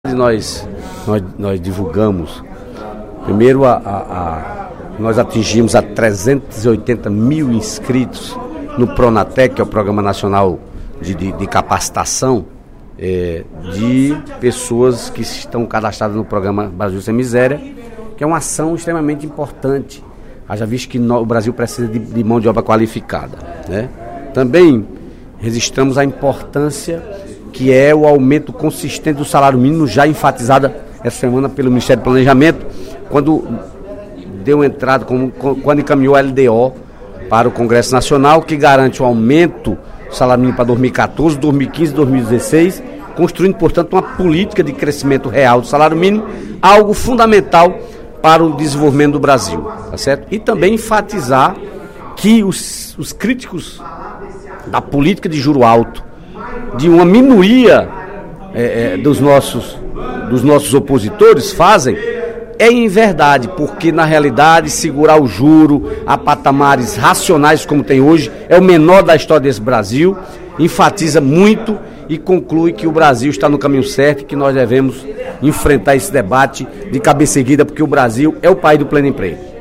Em pronunciamento durante o primeiro expediente da sessão plenária desta quarta-feira (17/04), o deputado Dedé Teixeira (PT) comentou o possível aumento do salário mínimo para R$ 719,48, em 2014, anunciado pelo Ministério do Planejamento na última semana.